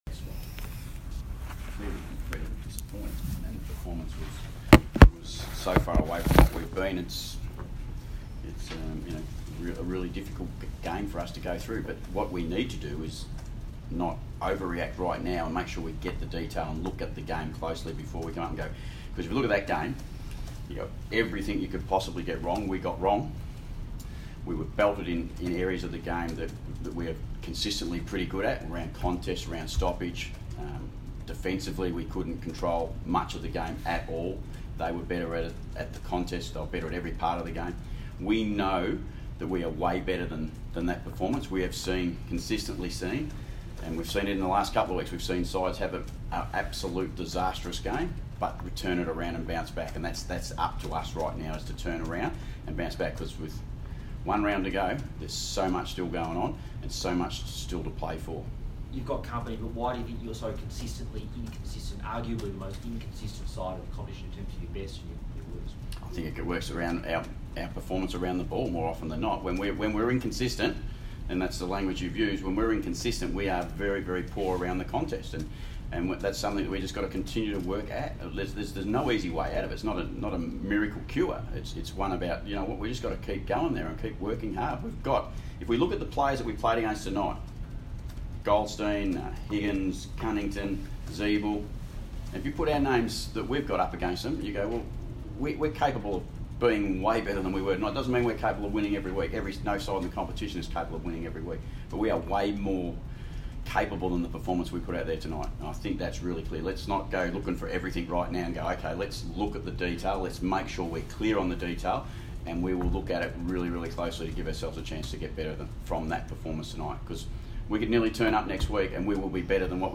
Ken Hinkley press conference - Saturday 17 August, 2019